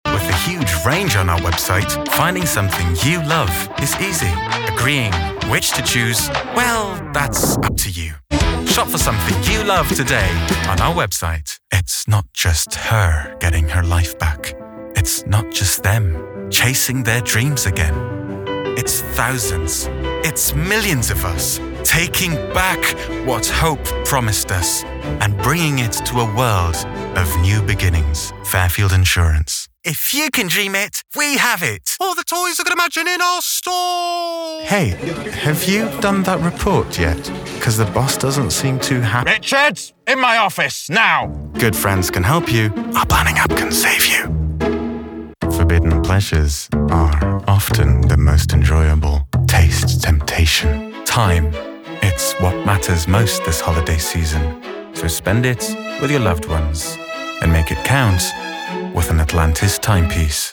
My voice is accent neutral American male, offering styles such as clear, comforting, conversational, professional and believable.